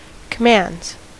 Ääntäminen
Ääntäminen US Tuntematon aksentti: IPA : /kə.ˈmɑːndz/ IPA : /kə.ˈmændz/ Haettu sana löytyi näillä lähdekielillä: englanti Käännöksiä ei löytynyt valitulle kohdekielelle.